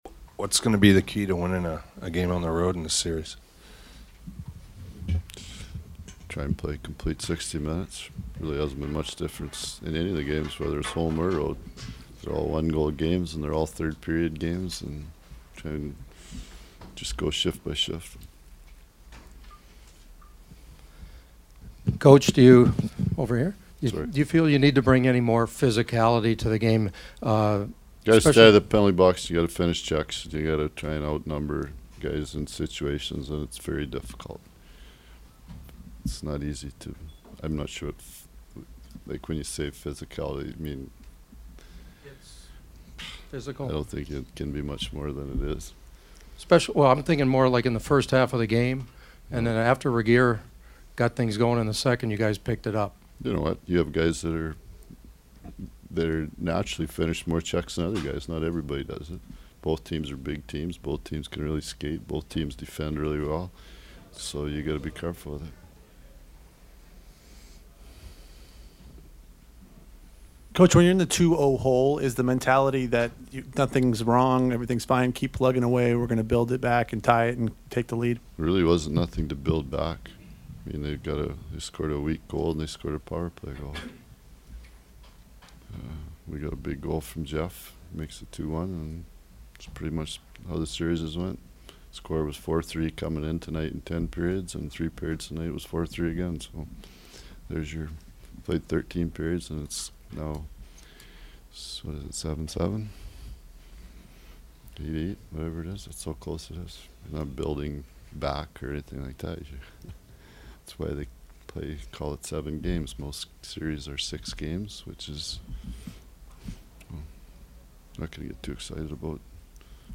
The following are my postgame chats from the locker room and they were all cautiously stoked about their chances during the rest of this series…
Kings coach Darryl Sutter: